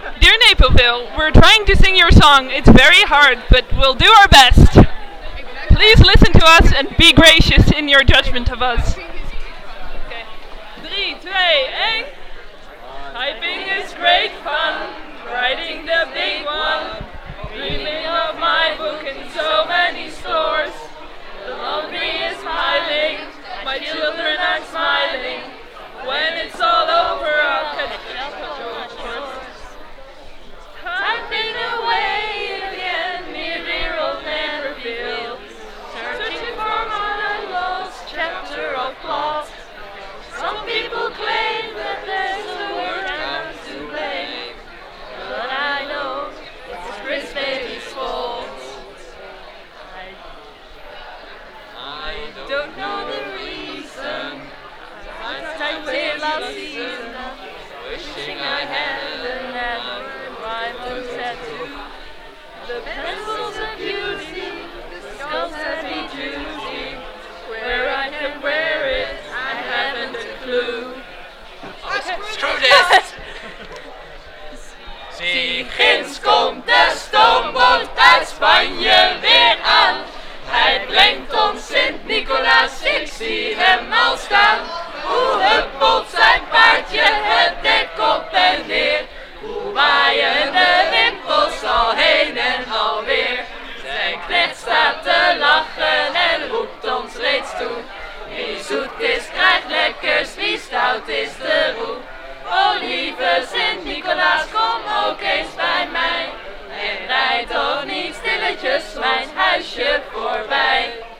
The stakes were to sing the other region's song, and so we did, at a late TGIO party.
You can imagine how those practice rounds went in a crowded pub. We crashed and burned rather spectacularly. In reparation, we also offer you a song of St Nicholas (our Santa Claus, who comes on December 5th). We hope you like it, and we apologise to any sensitive musical ears that might be listening.